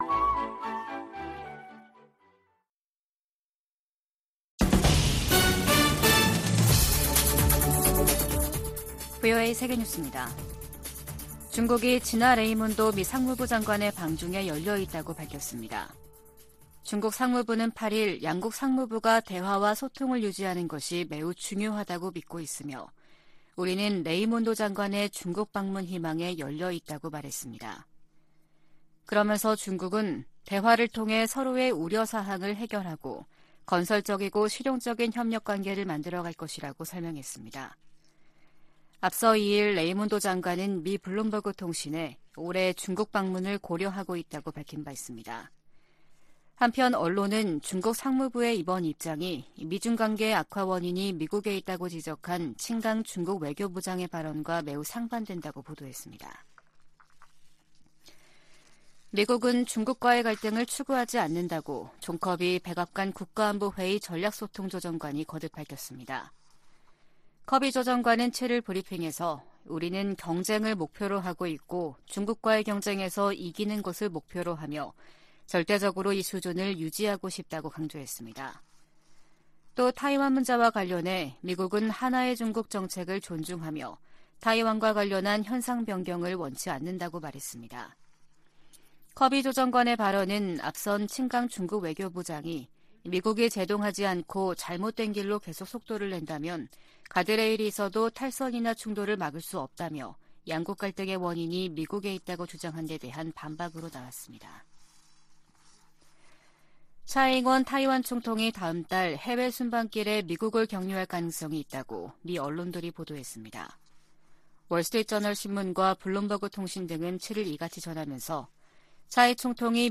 VOA 한국어 아침 뉴스 프로그램 '워싱턴 뉴스 광장' 2023년 3월 9일 방송입니다. 백악관은 윤석열 한국 대통령이 다음 달 26일 미국을 국빈 방문한다고 밝혔습니다. 미국 사이버사령관이 미국 정치에 개입하려 시도하는 상위 4개국으로 북한과 중국, 러시아, 이란을 꼽았습니다.